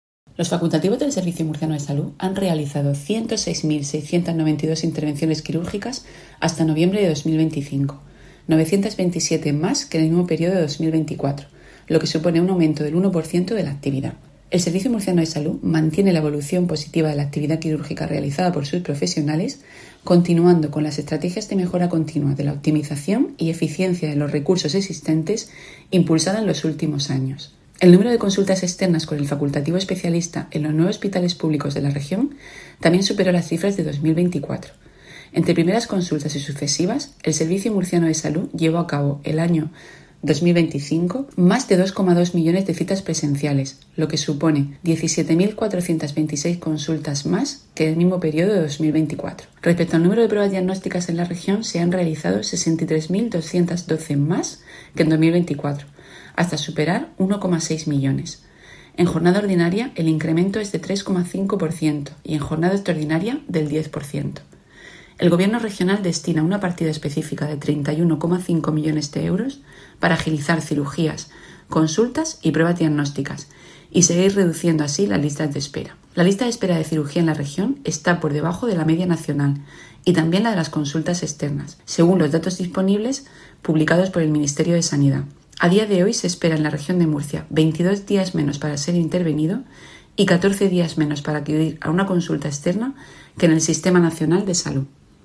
Sonido/ Declaraciones de la gerente del SMS, Isabel Ayala, sobre el balance de la actividad hospitalaria de 2025.